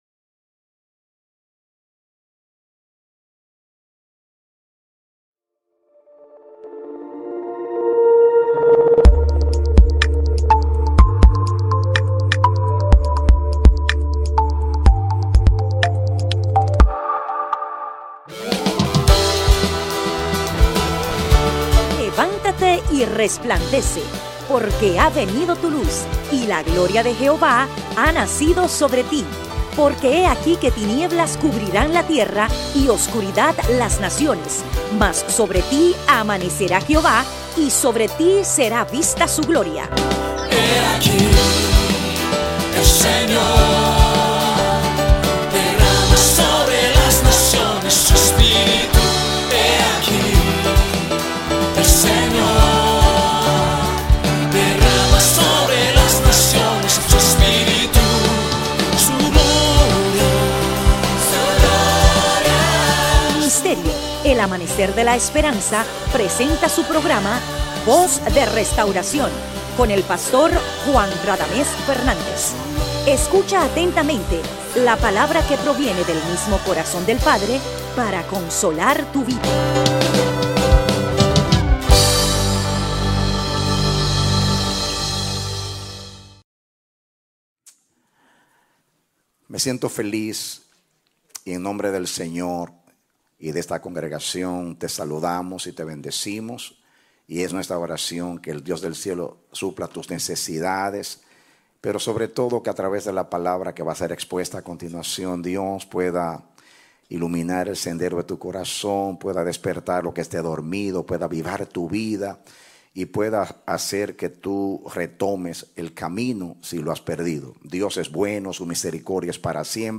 Predicado Domingo 1 de Abril, 2018